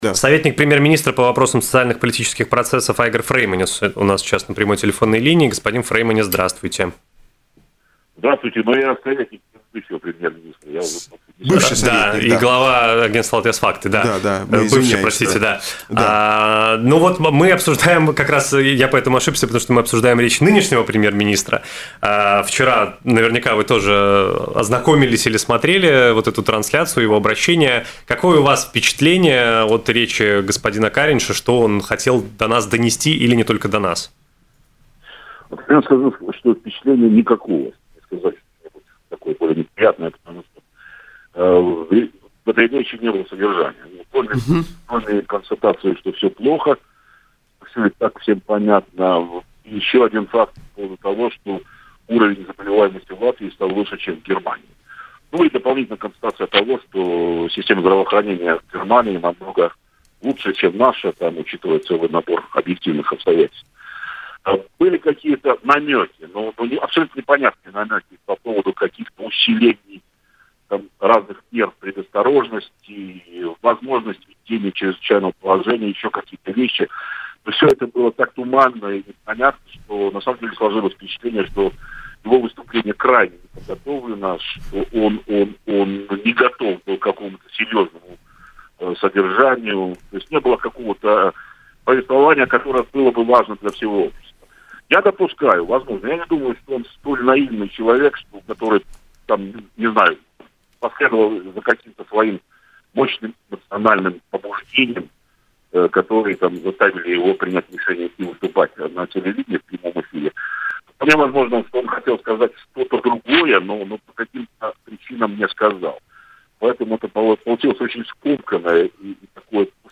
В эфире радио он тоже указал на неподготовленность премьера.